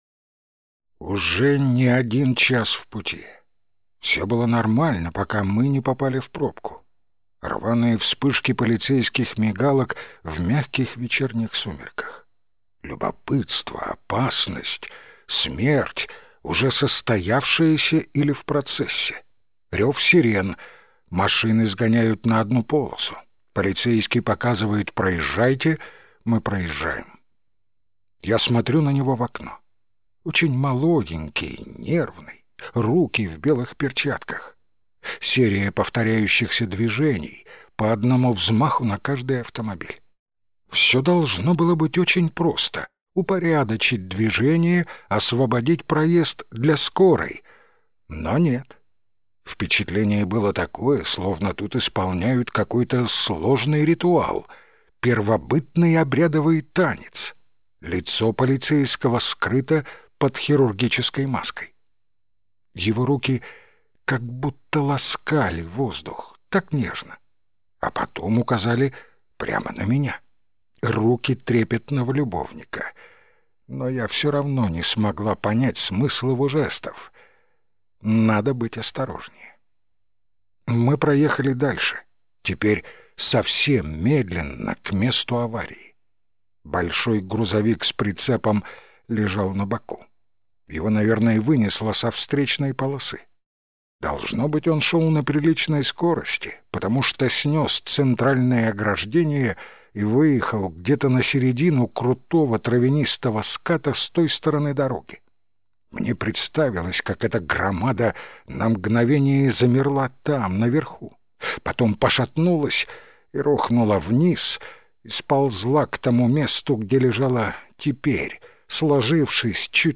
Аудиокнига Брошенные машины | Библиотека аудиокниг